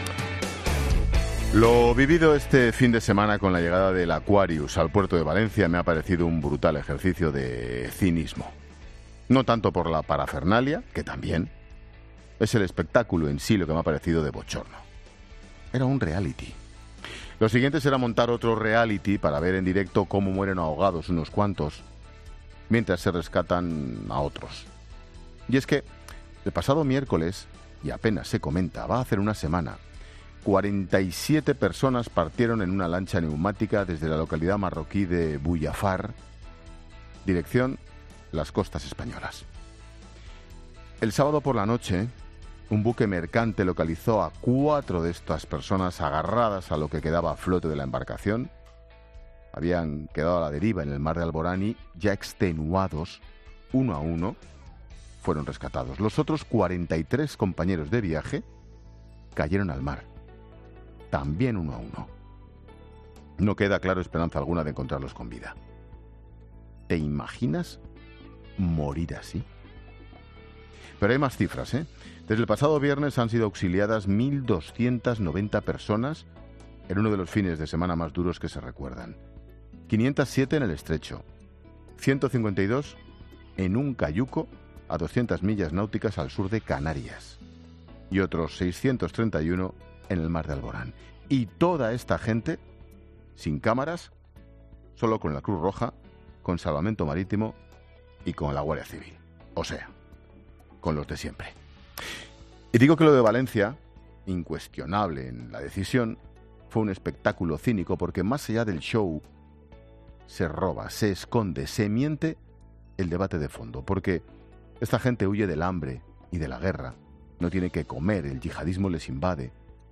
Monólogo de Expósito
El comentario de Ángel Expósito sobre el drama de la inmigración.